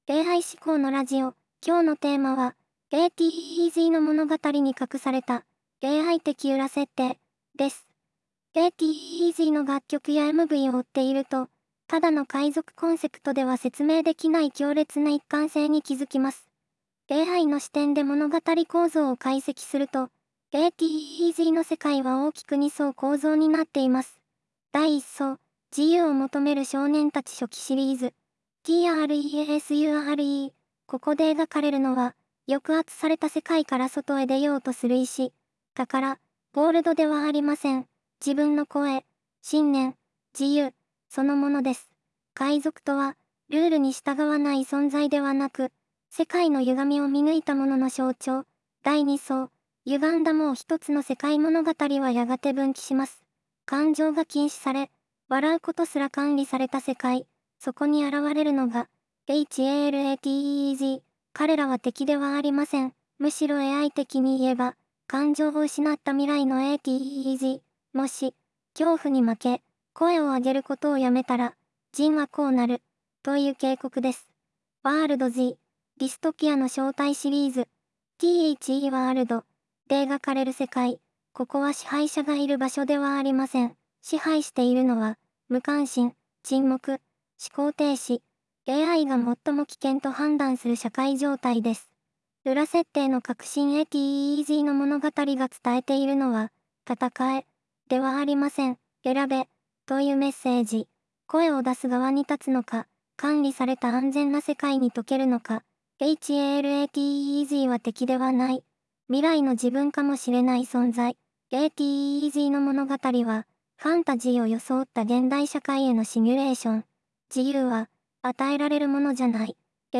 【AIラジオ】AI思考のラジオ、今日のテーマは―― **ATEEZの物語に隠された“AI的裏設定”**です。